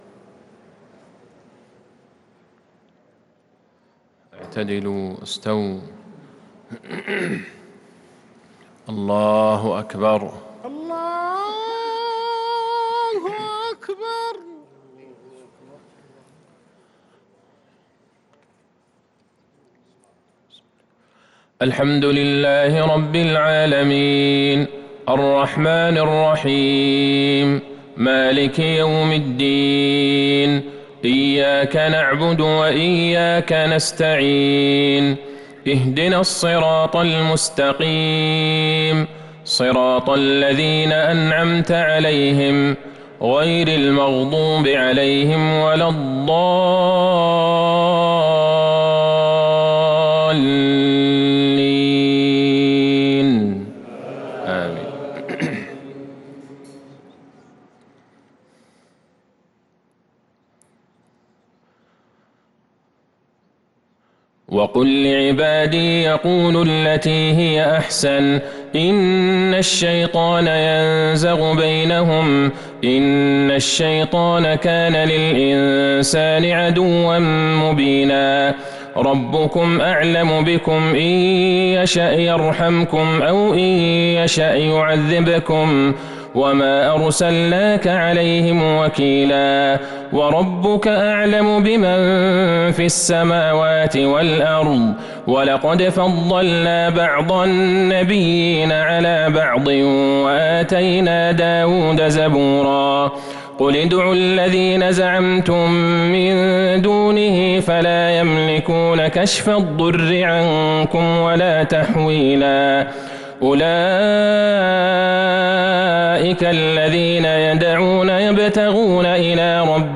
صلاة العشاء للقارئ عبدالله البعيجان 25 شعبان 1443 هـ